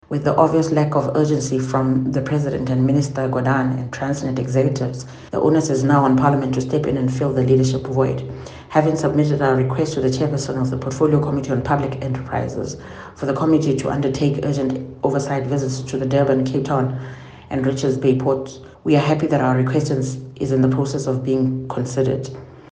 Die DA se nasionale woordvoerder vir Openbare Ondernemings, Mimmy Gondwe, sê albei leiers het hul grondwetlike pligte versuim, om die ekonomie te beskerm tydens die ergste krisis vir handel- en uitvoerhawens in dekades. Gondwe doen ‘n beroep op Ramaphosa en Gordhan om die leiding te neem en met belanghebbendes in die uitvoersektor en Transnet-bestuurders te skakel en aandag aan agterstande te gee en langtermynoplossings te vind: